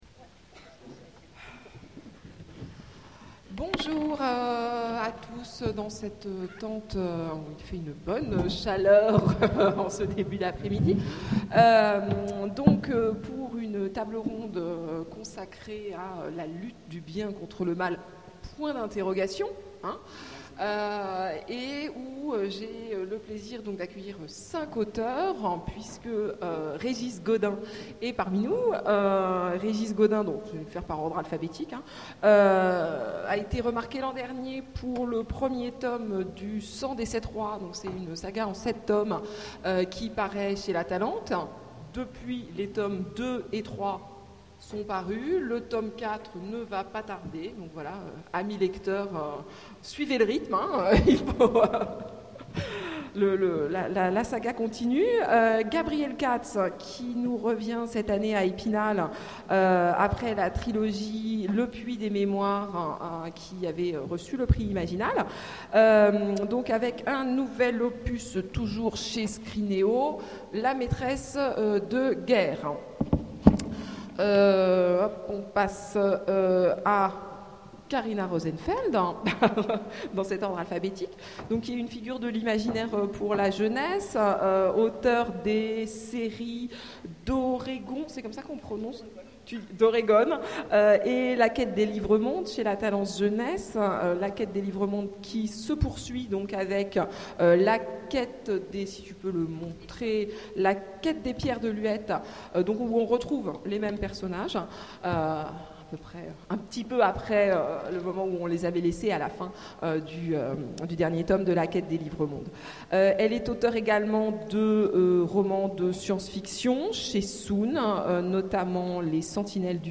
Imaginales 2014 : Conférence Les romans de fantasy, la lutte du bien contre le mal?